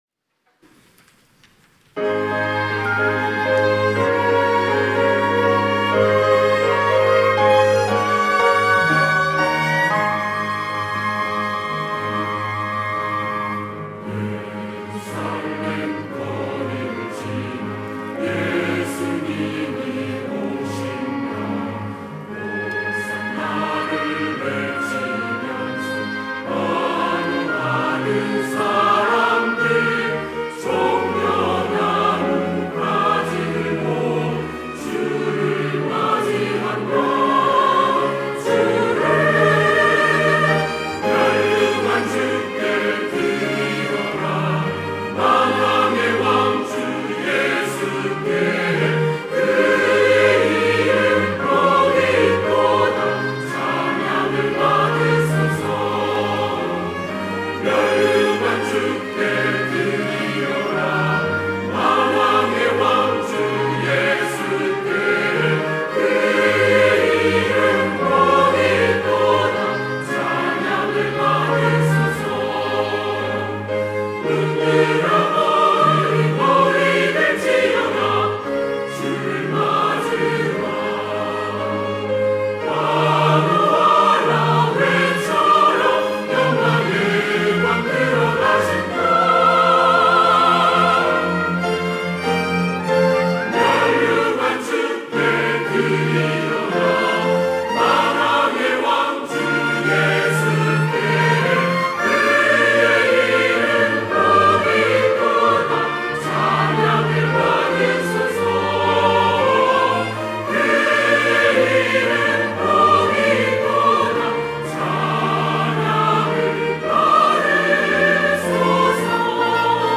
할렐루야(주일2부) - 면류관 드리세 호산나
찬양대